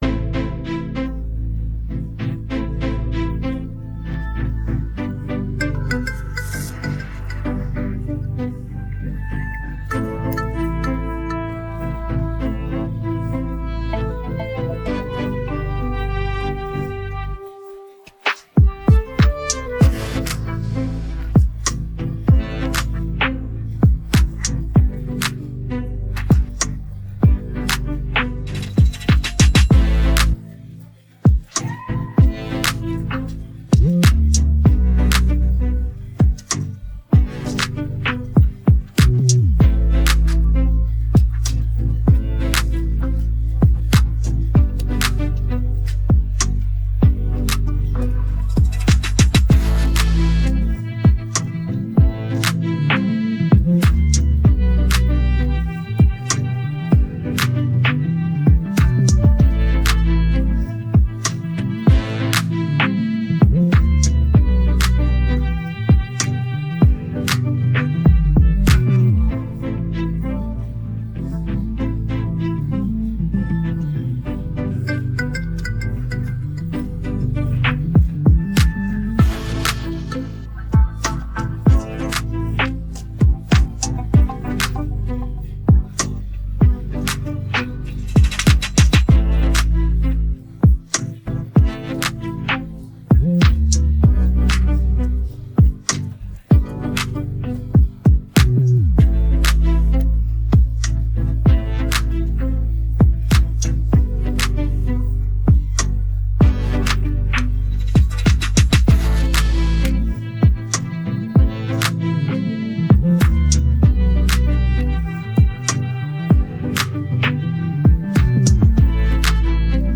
Afro popDancehall